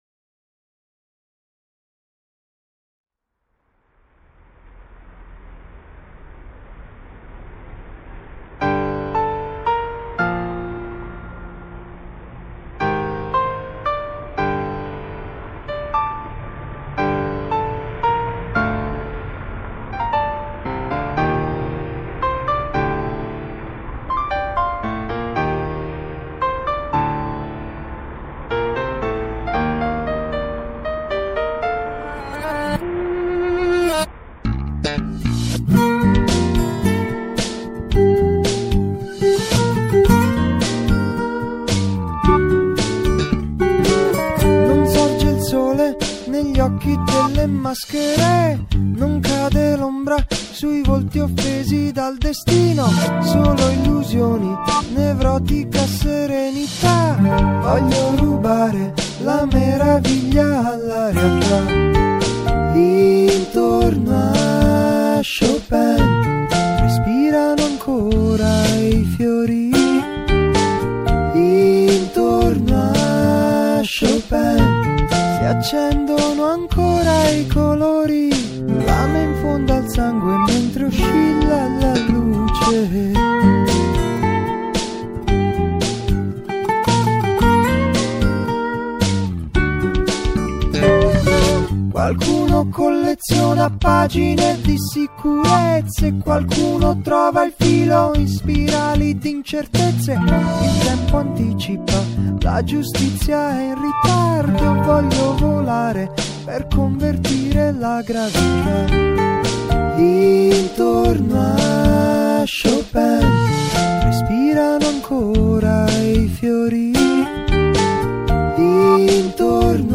GenerePop